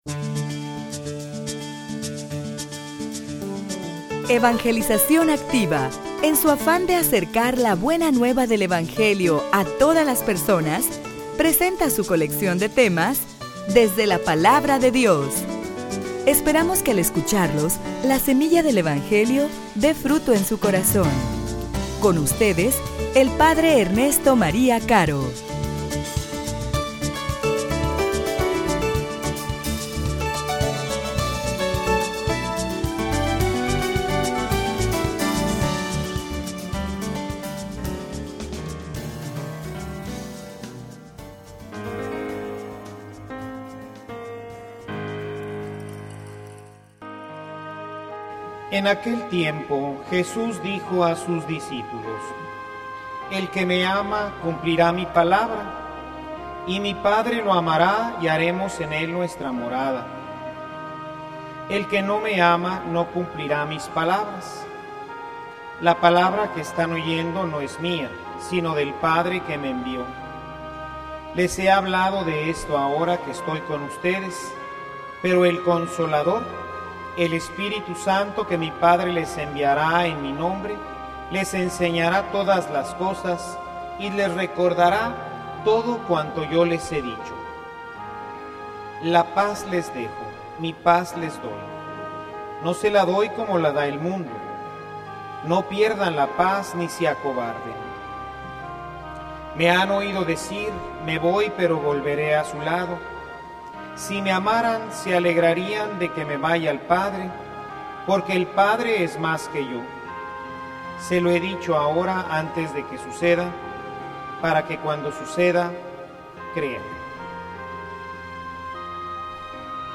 homilia_Ejercicio_de_fe.mp3